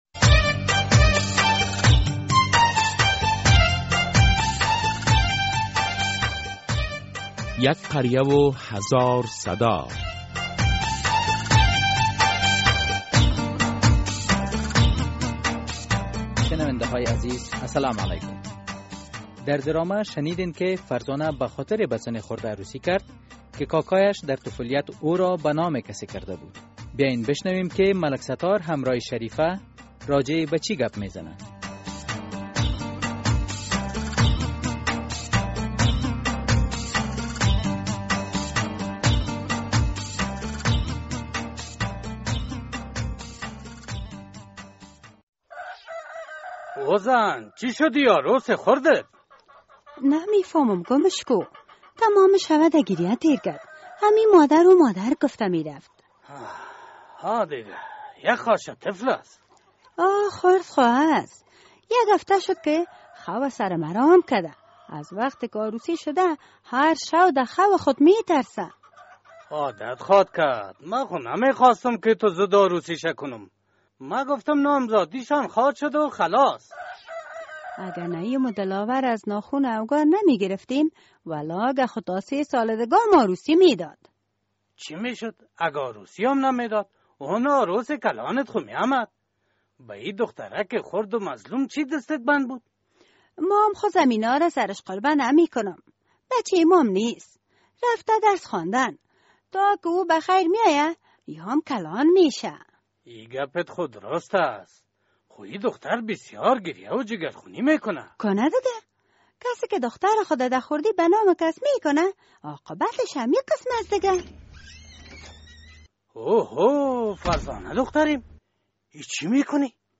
در این درامه که موضوعات مختلف مدنی، دینی، اخلاقی، اجتماعی و حقوقی بیان می‌گردد هر هفته به روز های دوشنبه ساعت ۳:۳۰ عصر از رادیو آزادی نشر می گردد.